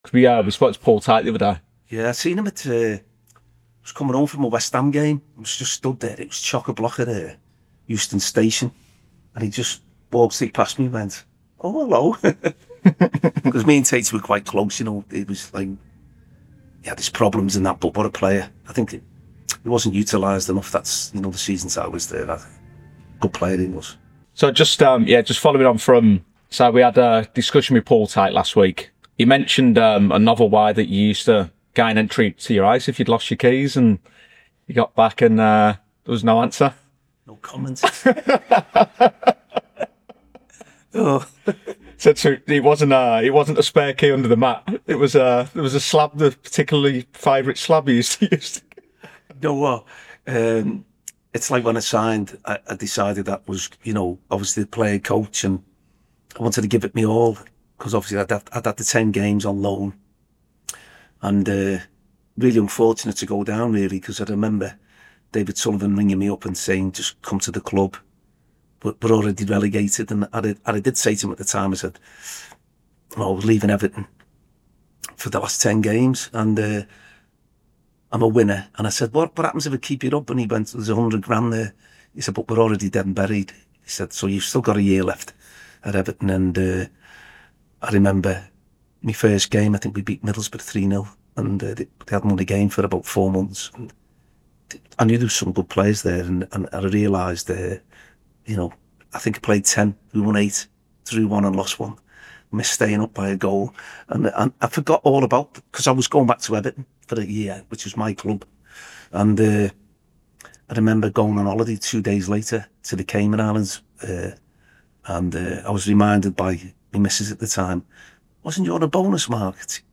In this powerful full-length interview, Mark Ward opens up like never before.